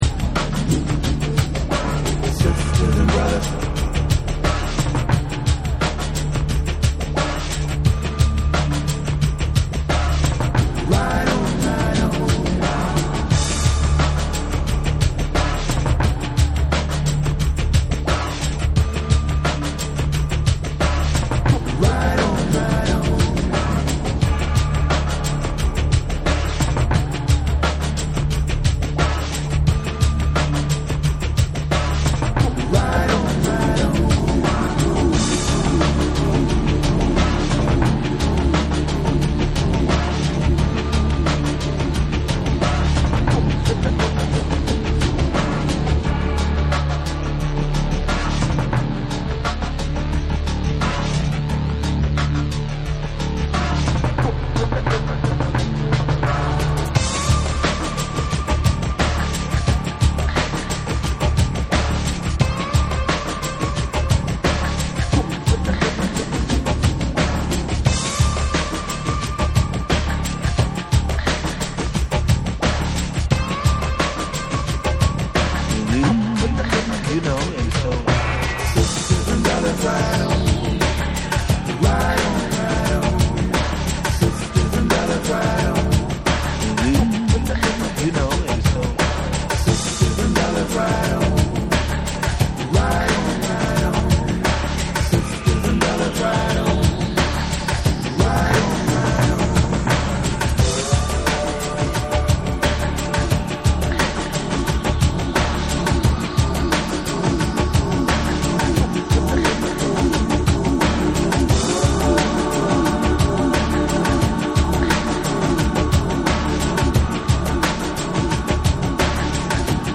サイケデリックな世界へと引きずり込まれるブルージー・ダブ・サウンド
BREAKBEATS